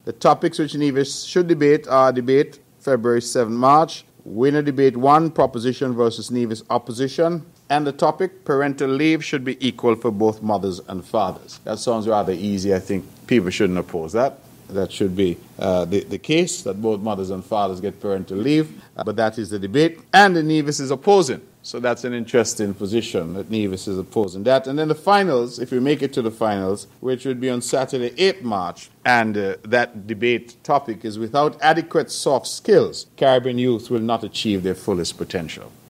As the reigning champions, Team Nevis will automatically advance to the semifinals. This is Premier, the Hon. Mark Brantley: